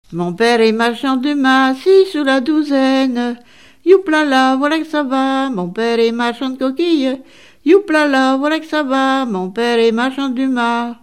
Mémoires et Patrimoines vivants - RaddO est une base de données d'archives iconographiques et sonores.
Genre énumérative
Catégorie Pièce musicale inédite